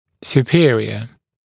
[s(j)uː’pɪərɪə][с(й)у:’пиэриэ]верхний, расположенный выше; превосходный, очень хороший